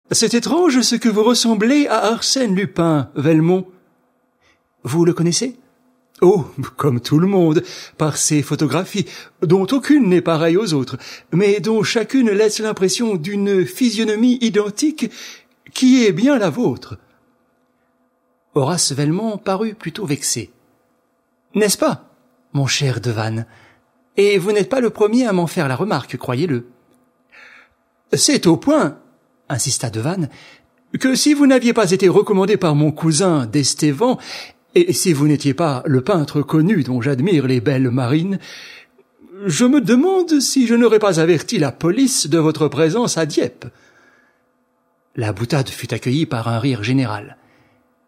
Click for an excerpt - Arsène Lupin - Herlock Sholmes arrive trop tard de Maurice Leblanc